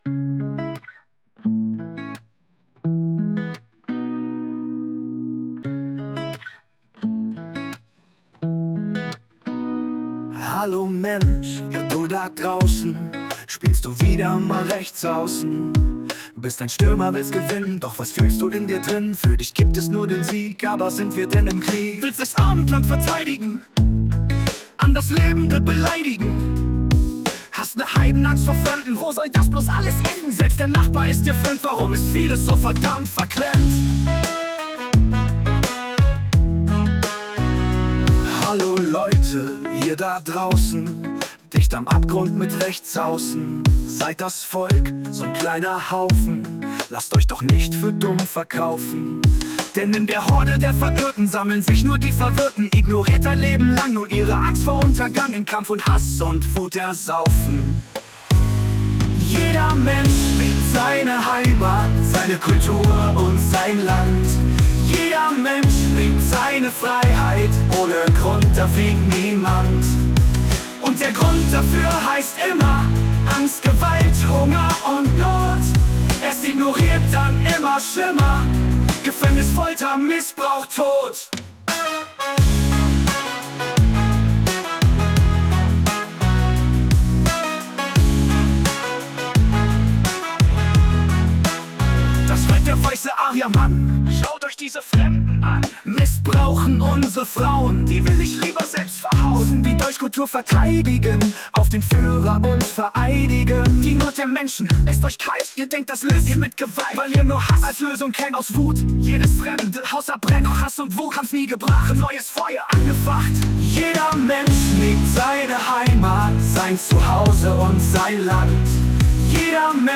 PopBallad male Vocals